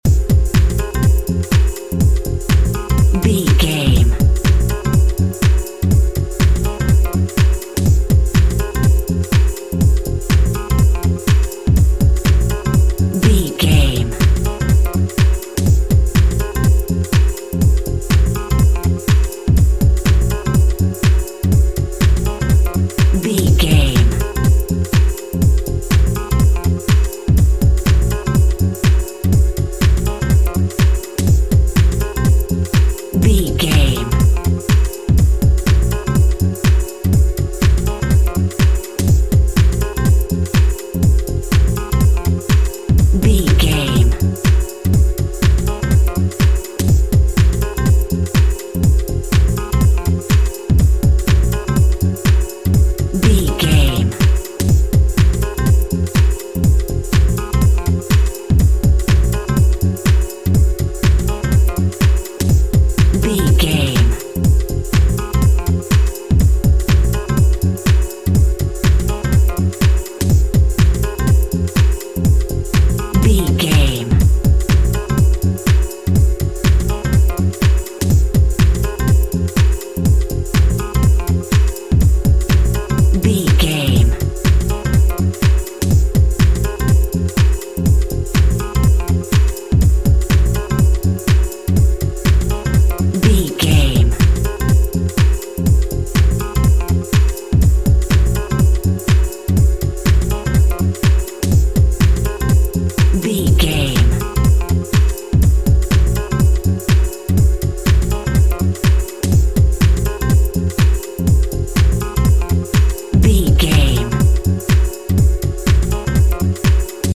Aeolian/Minor
groovy
dark
futuristic
funky
energetic
driving
bass guitar
drums
drum machine
synthesiser
electric guitar
Lounge
chill out
nu jazz
downtempo
on hold music
synth leads
synth bass